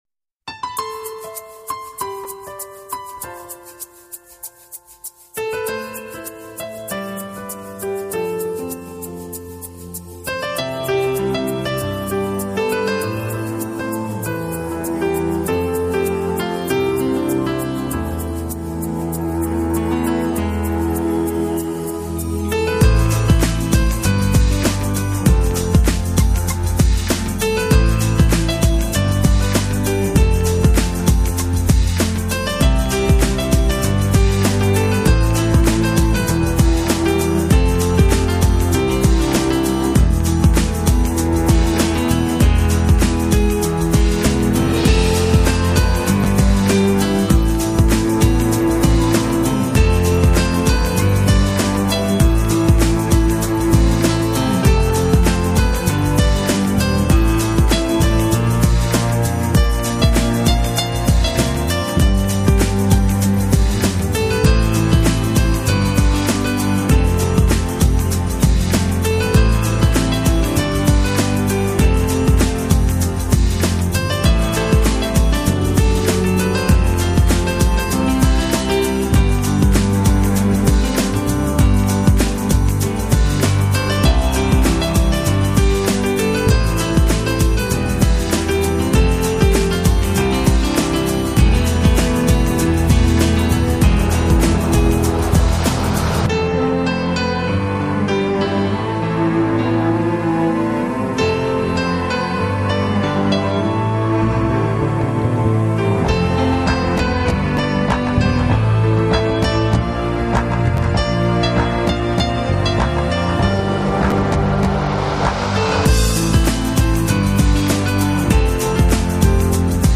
【纯色钢琴】